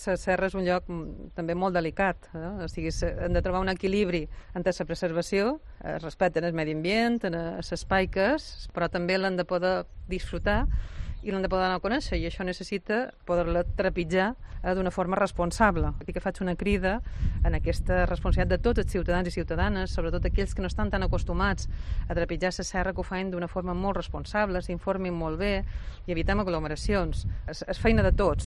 Catalina Cladera, presidenta del Consell de Mallorca